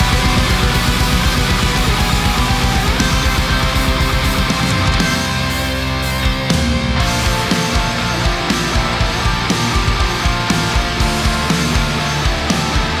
Mix Help: Metal Gitarren
mixrough_ampsim-wav.88640